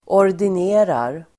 Ladda ner uttalet
Uttal: [år_din'e:rar]